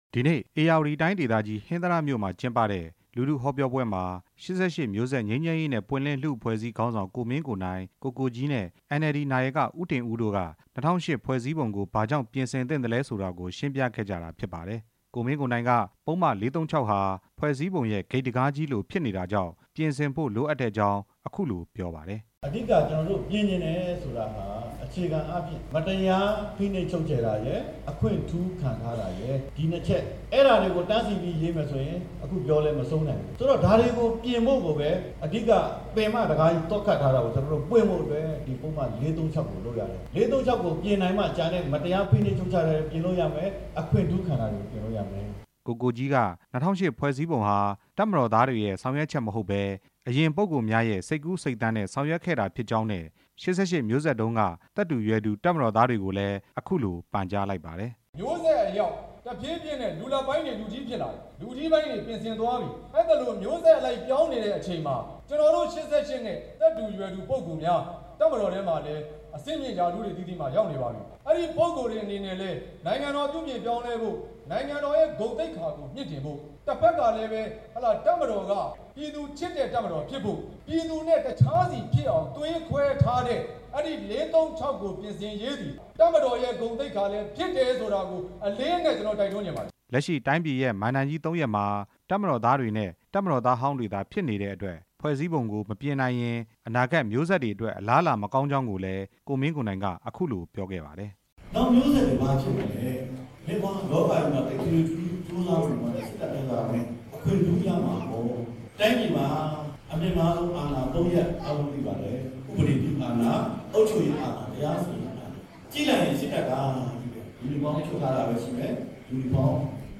အမျိုးသားဒီမိုကရေစီအဖွဲ့ချုပ် နဲ့ ၈၈ မျိုးဆက်ငြိမ်းချမ်းရေး နဲ့ပွင့်လင်းလူ့အဖွဲ့အစည်းတို့ ဧရာဝတီတိုင်း ဒေသကြီး ဟင်္သာတမြို့မှာ ဒီကနေ့ကျင်းပခဲ့တဲ့ လူထုဟောပြောပွဲ မှာ ပြောကြားခဲ့ကြတာပါ။
ဟောပြောပွဲကို လူထုပရိသတ် ၂ ထောင်နီးပါးတက်ရောက်ခဲ့ကြပါတယ်။